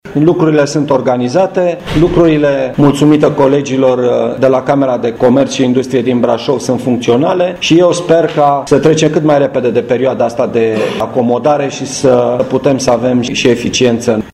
La Camera de Comerț și Industrie Brașov a fost prezent și prefectul Brașovului, Marian Rasaliu, care a spus: